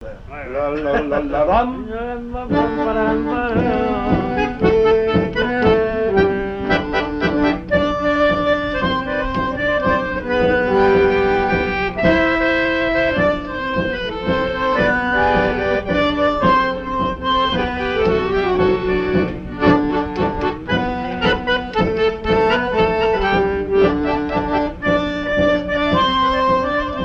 Mémoires et Patrimoines vivants - RaddO est une base de données d'archives iconographiques et sonores.
danse : tango
Pièce musicale inédite